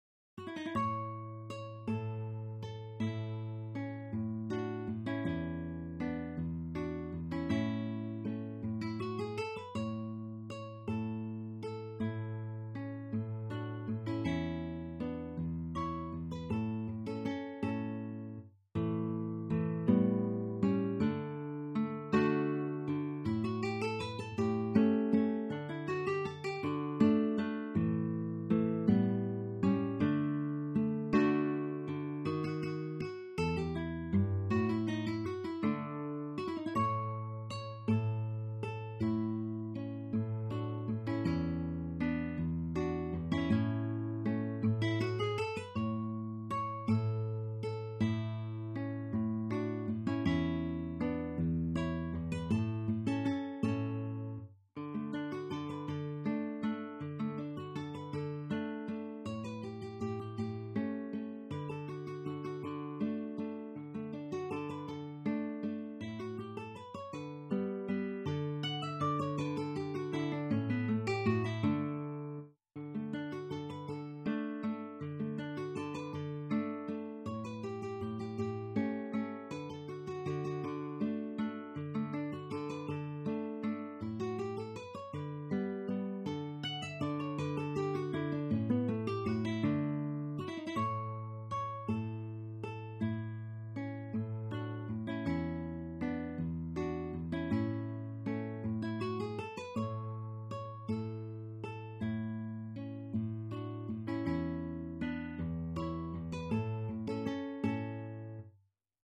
In effetti, la sua musica è scorrevole e piacevolissima e ve la consiglio per esercitazioni senza soverchie difficoltà.